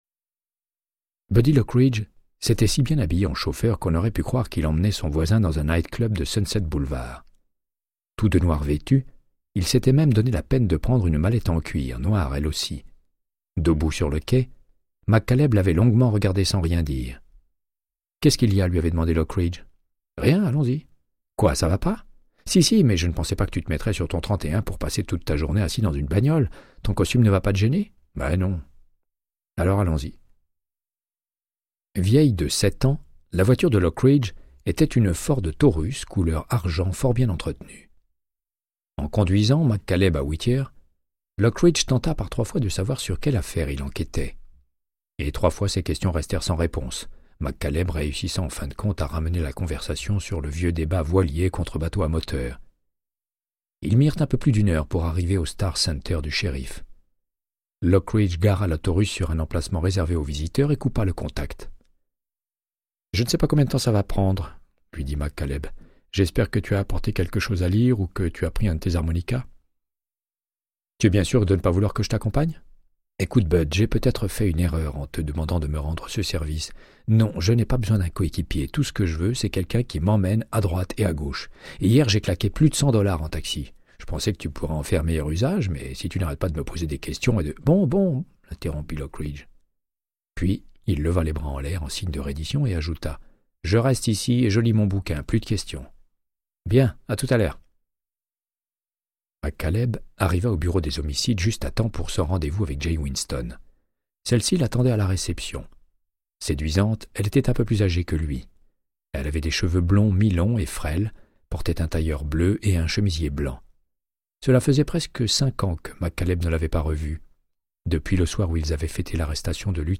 Audiobook = Créance de sang, de Michael Connellly - 32